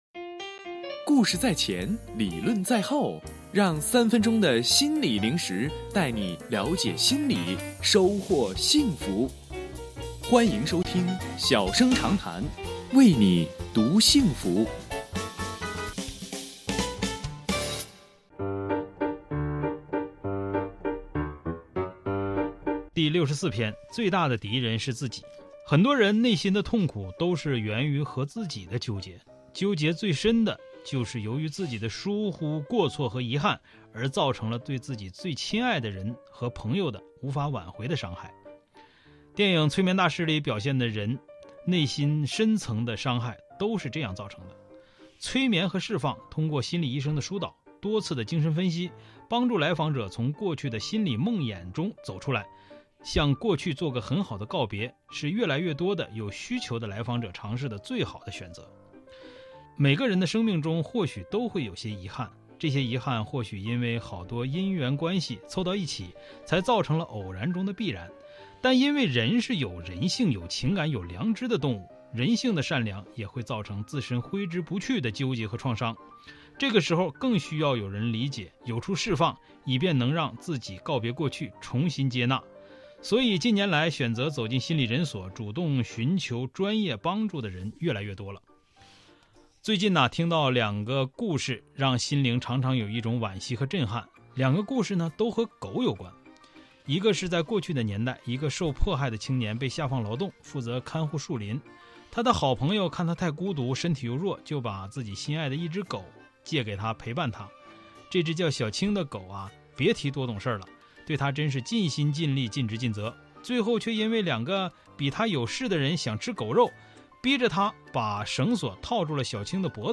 音频来源：吉林广播电视台 新闻综合广播